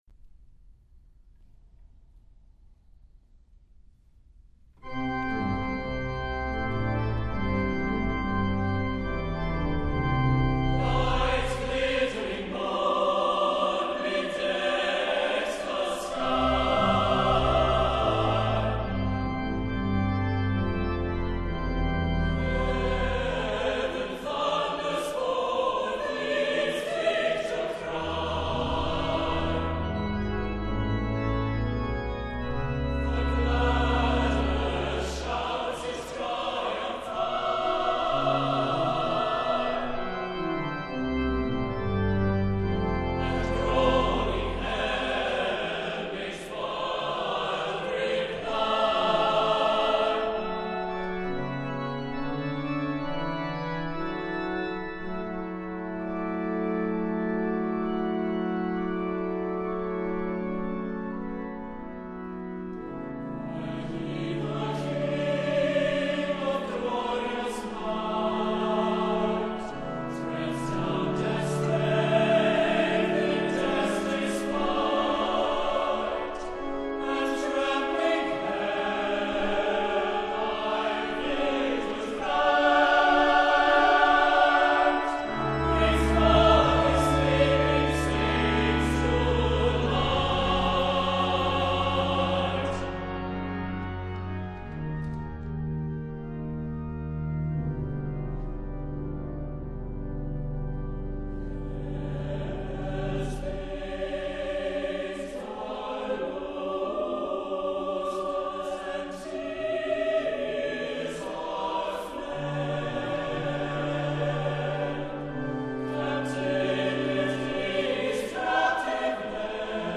• Music Type: Choral
• Voicing: SATB
• Accompaniment: Brass Quintet, Organ